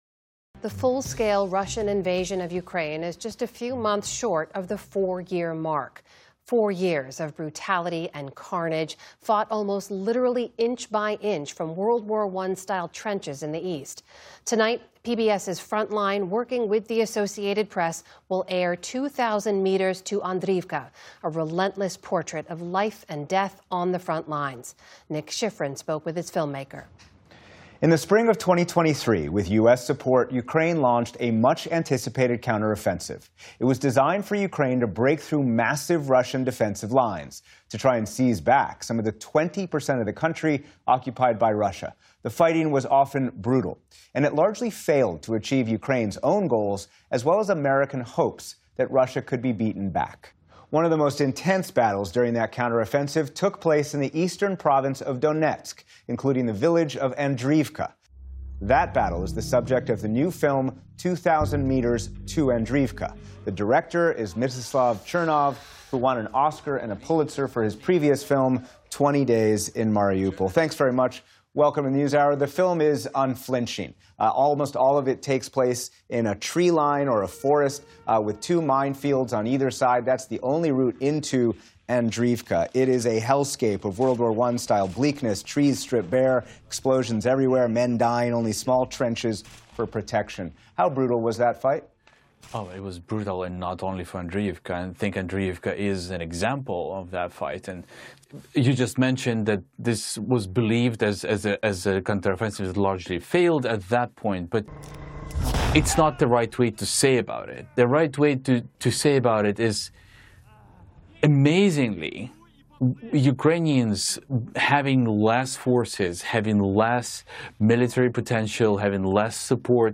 PBS's Frontline, working with the Associated Press, will air "2000 Meters to Andriivka," a relentless portrait of life and death on the frontlines. Nick Schifrin spoke with filmmaker Mstyslav Chernov.